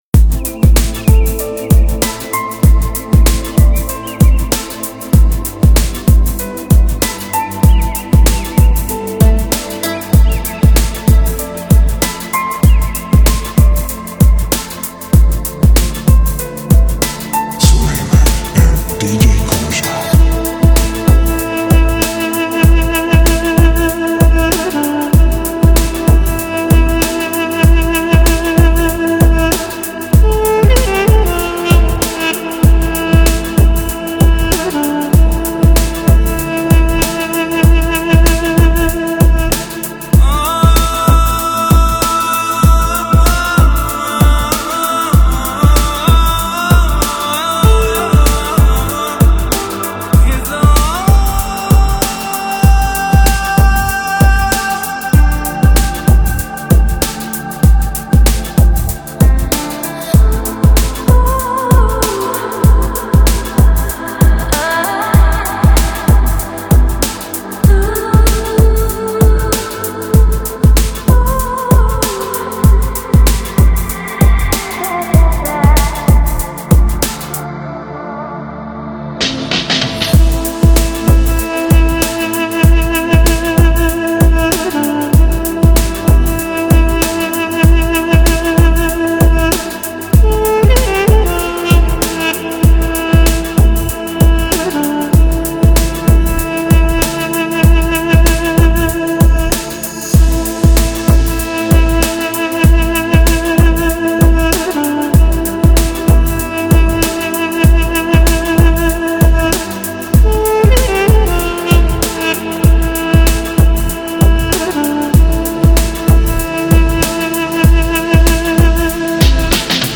красивая музыка
душевная музыка Размер файла